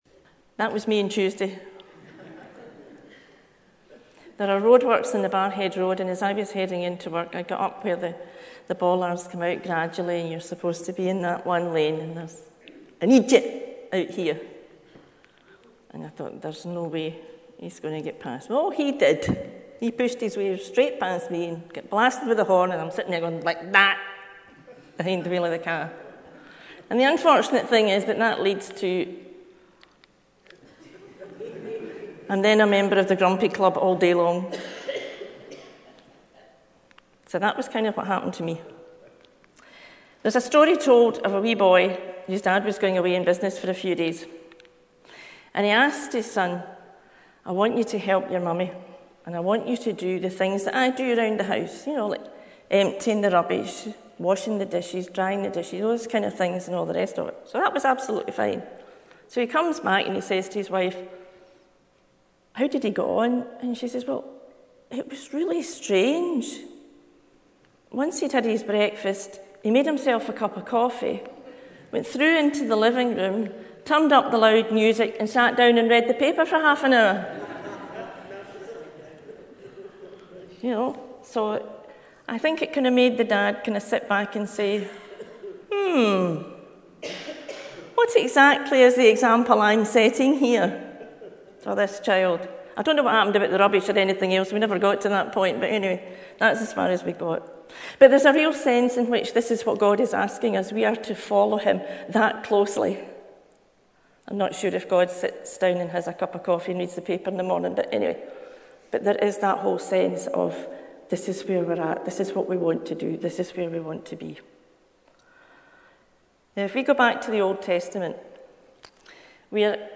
0_04-sermon-12.mp3